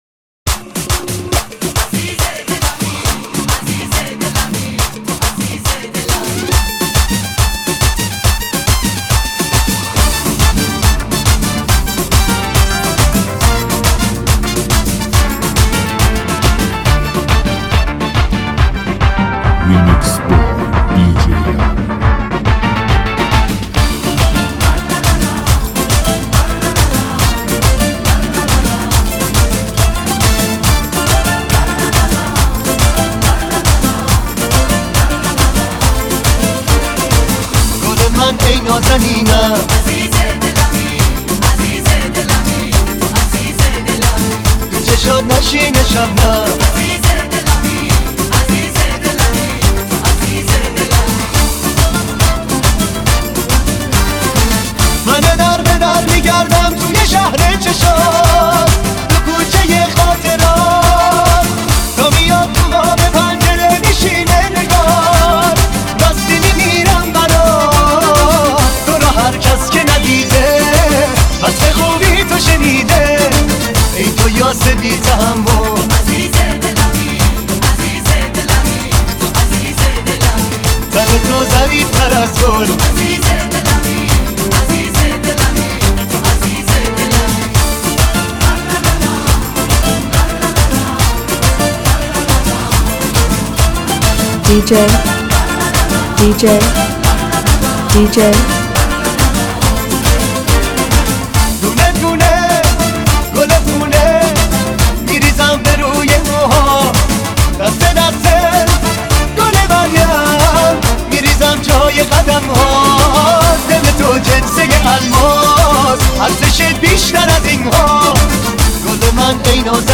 لذت بردن از موسیقی شاد و پرانرژی، هم‌اکنون در سایت ما.
ریمیکس شاد و پرانرژی